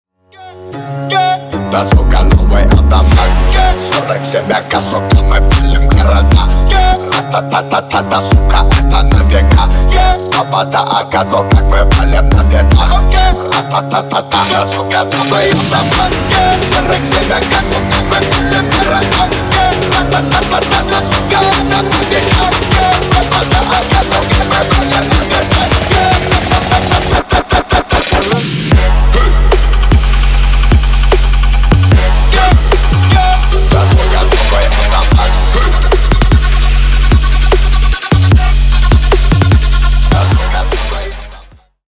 Ремикс # Рэп и Хип Хоп
клубные
громкие # ритмичные